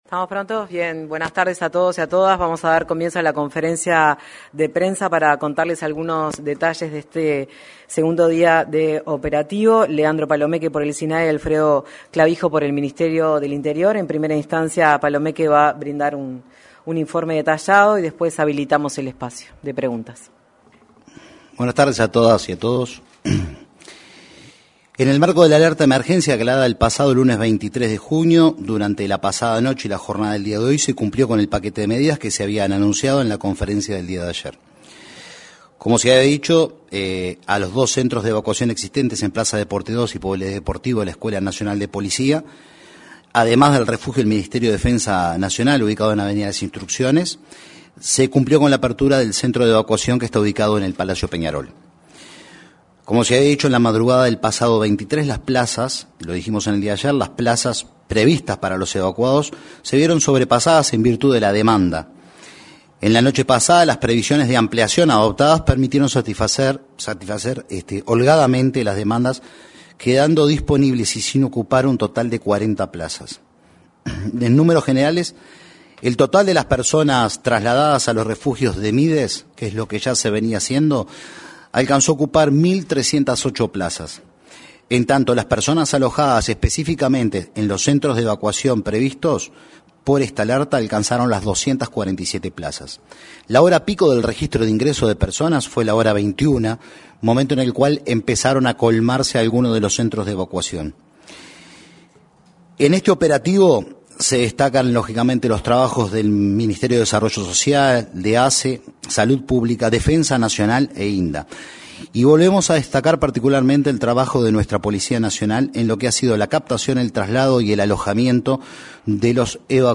Conferencia de prensa del Sistema Nacional de Emergencias
Conferencia de prensa del Sistema Nacional de Emergencias 25/06/2025 Compartir Facebook X Copiar enlace WhatsApp LinkedIn El director del Sistema Nacional de Emergencias (Sinae), Leandro Palomeque, y el subdirector de la Policía Nacional, Alfredo Clavijo, informaron, en una conferencia de prensa en la Torre Ejecutiva, sobre las acciones para proteger a personas en situación de calle ante las bajas temperaturas.